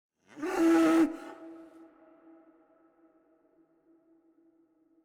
sfx_bull_vocal_bucked_off.opus